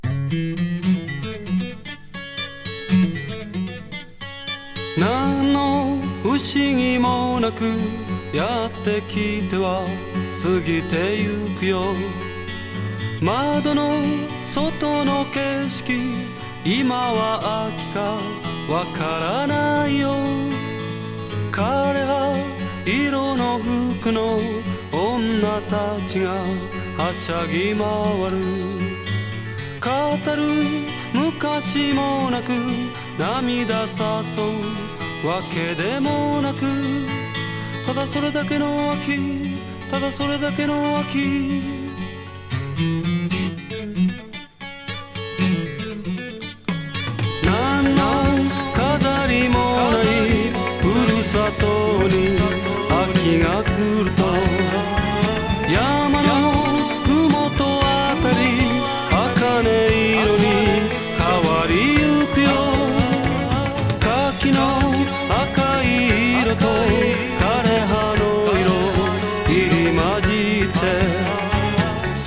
朴訥としたヴォーカルと、少し悲し気なメロディーが秋っぽい、フォーキー・グルーヴィー・ナンバー。